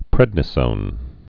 (prĕdnĭ-sōn,-zōn)